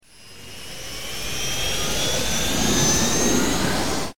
shipmove.ogg